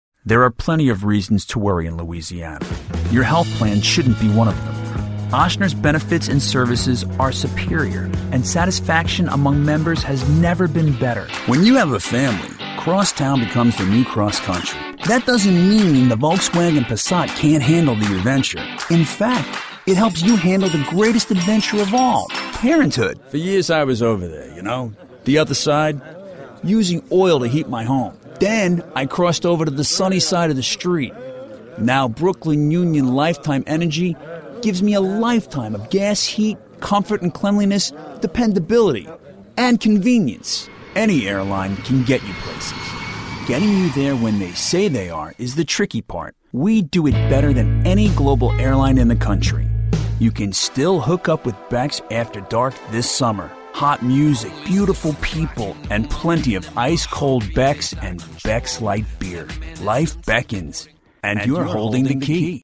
Adult, Young Adult
mid atlantic
standard us
commercial
friendly
well spoken